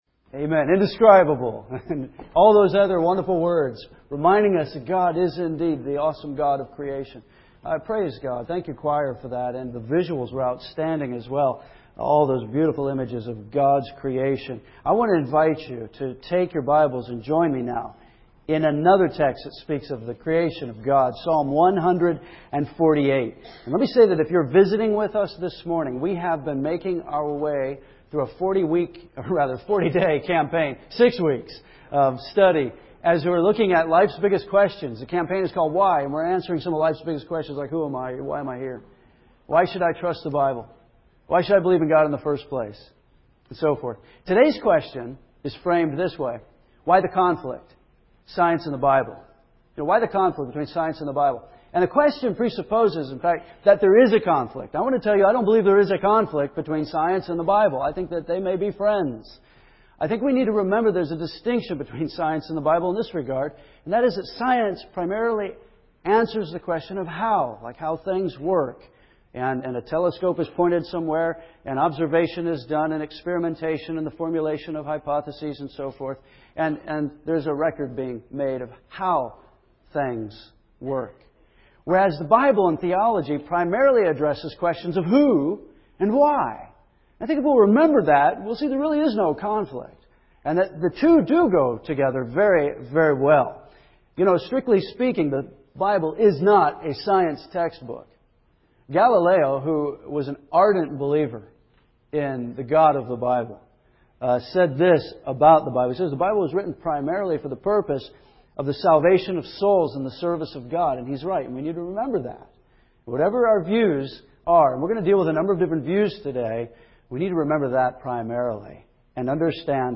First Baptist Church Henderson, KY (4-27-08) (AM)
You will hear the singing of this psalm in Hebrew and the words of both Hebrew and English will be on the wall.